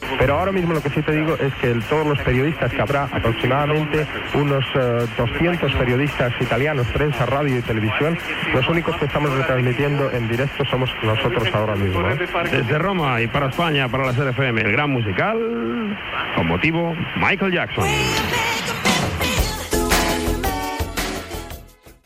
Informació, des de Roma, abans de l'inici de la roda de premsa de la gira europea del cantant Michael Jackson